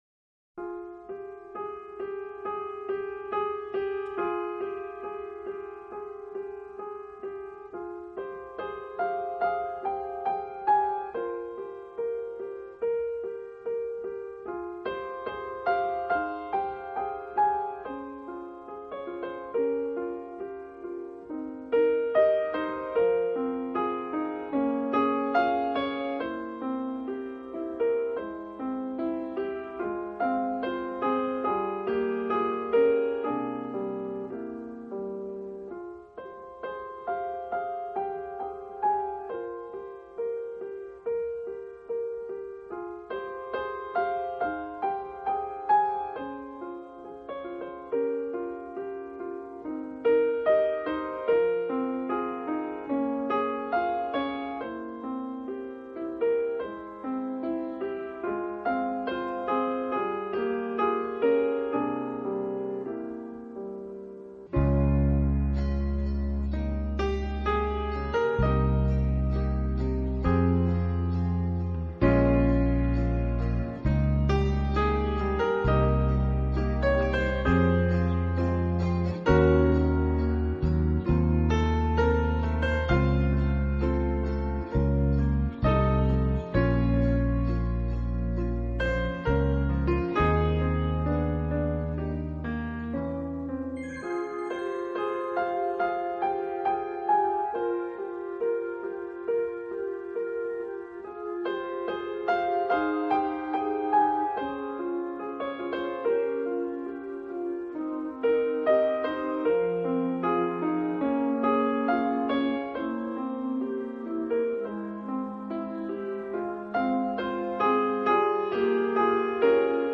钢琴纯乐